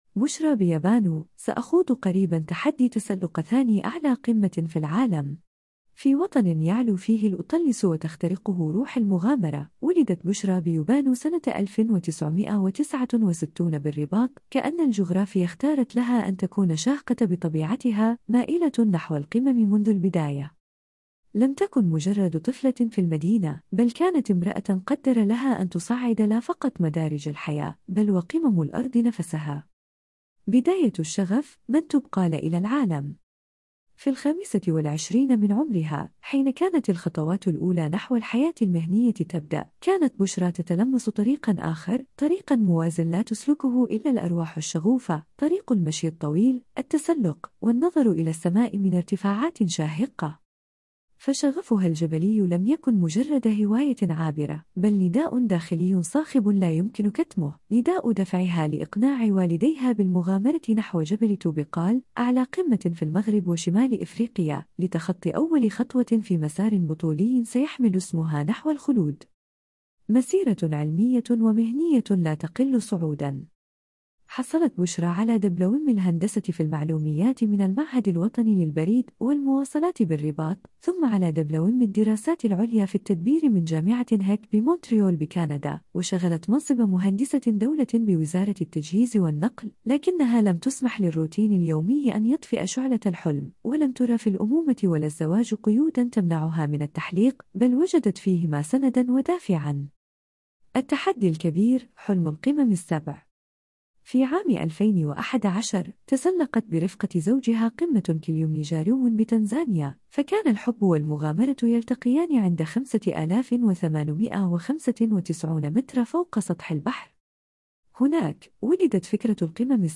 حوار بشرى بيبانو